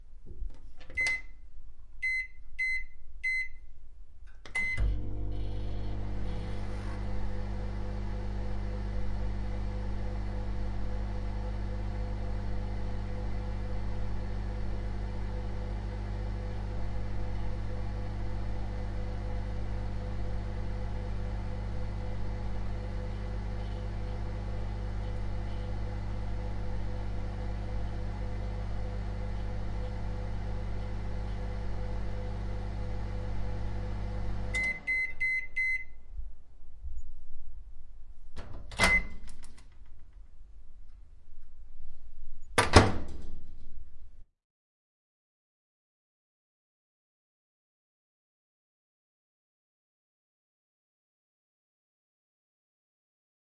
微波炉声音
描述：在微波炉上设定时间。微波炉在运行30秒，操作后有微波门打开和关闭声音。